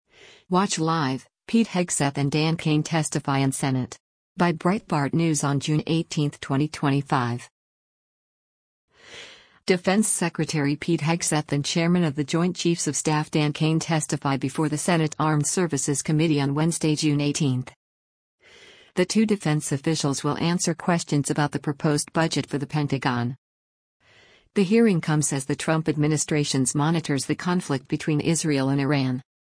Defense Secretary Pete Hegseth and Chairman of the Joint Chiefs of Staff Dan Caine testify before the Senate Armed Services Committee on Wednesday, June 18.